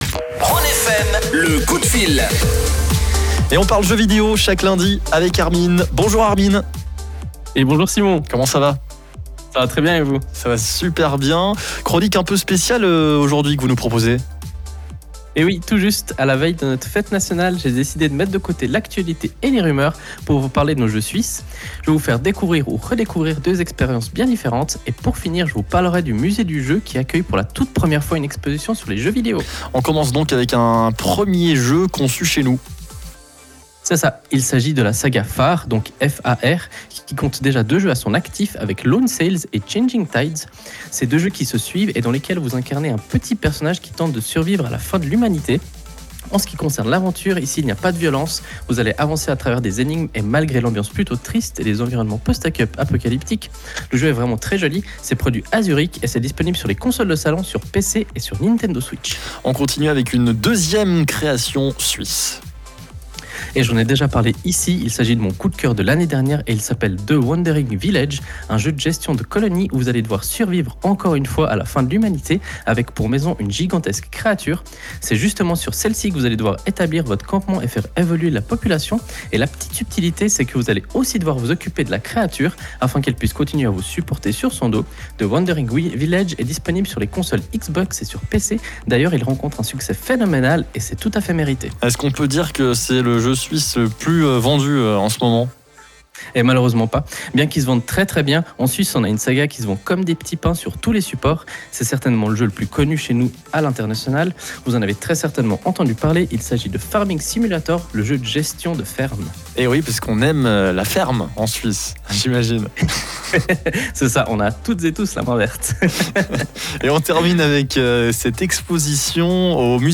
Pour terminer, comme toujours, vous pouvez réécouter le direct via le lien qui se trouve jsute en dessus.